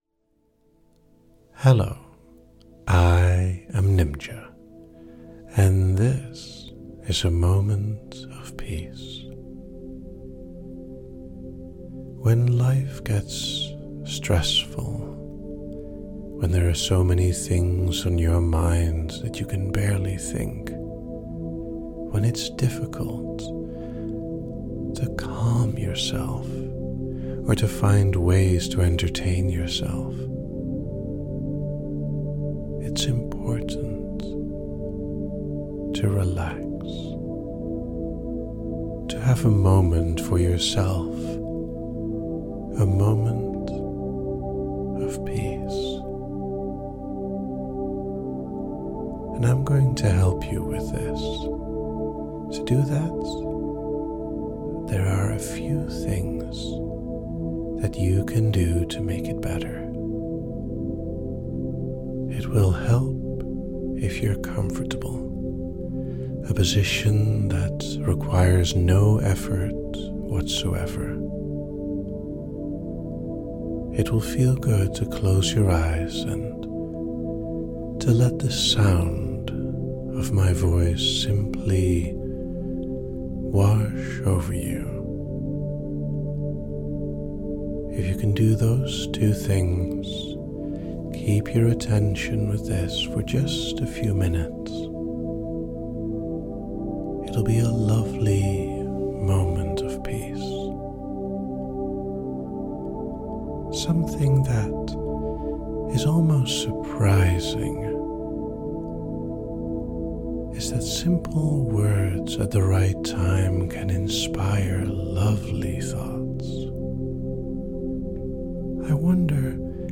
A nice step away from any busy or stressful times you might have. - A gentle guide into your safe place.